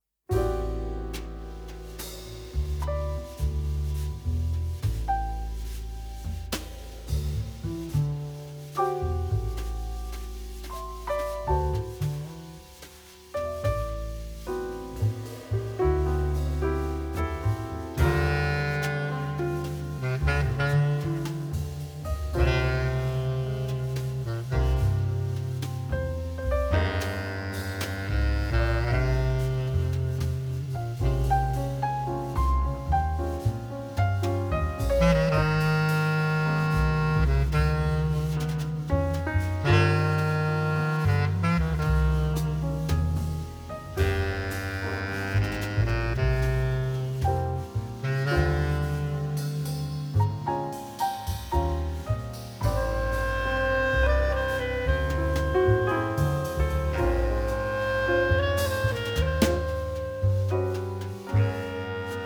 The Best In British Jazz
Recorded at Fish Factory Studio, London 2016